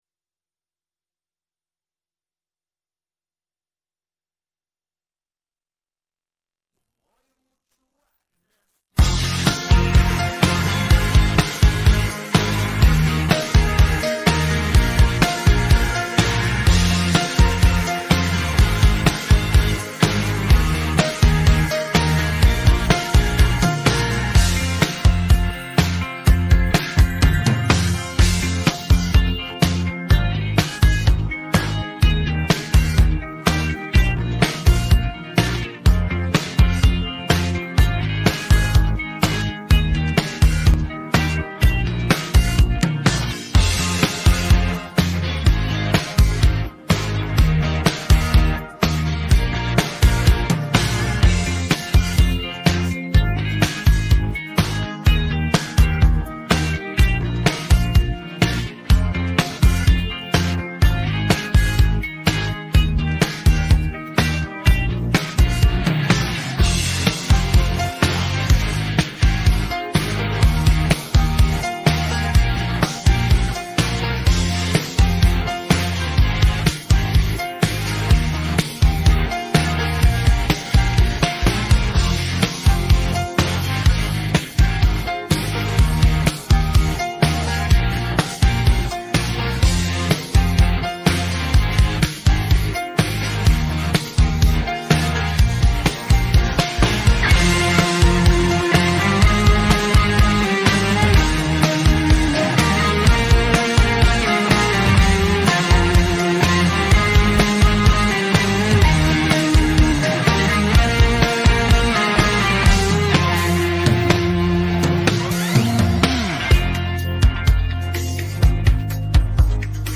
pop rock караоке